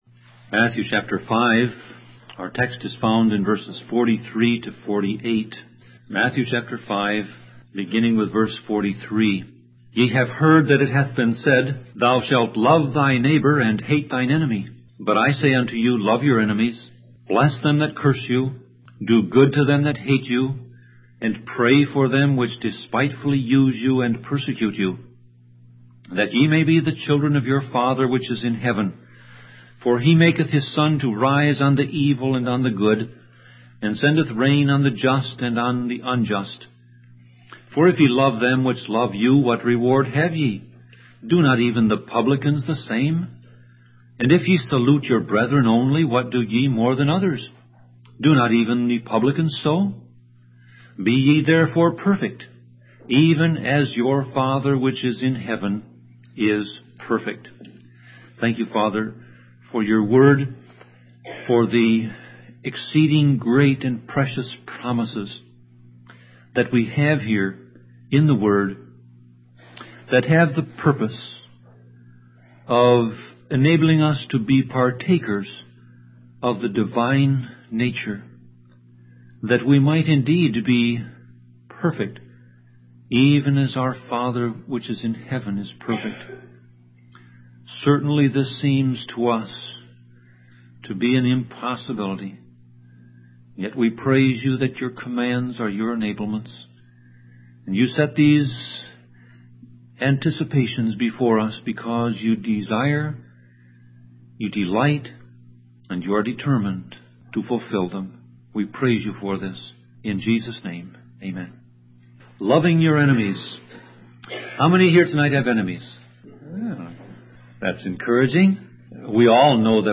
Sermon Audio Passage: Matthew 5:43-48